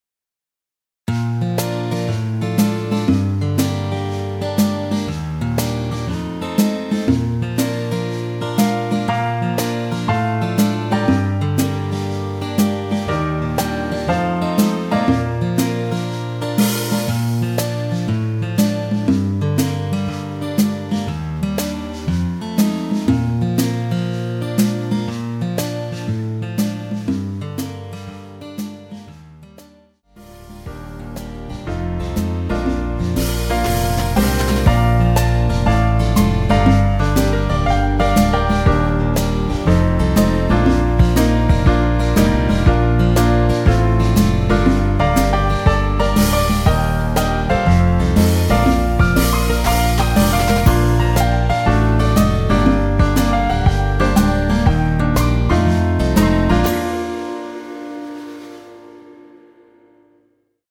엔딩이 페이드 아웃에 너무 길어서 4마디로 엔딩을 만들었습니다.(미리듣기 참조)
원키에서(-2)내린 MR 입니다.
Bb
앞부분30초, 뒷부분30초씩 편집해서 올려 드리고 있습니다.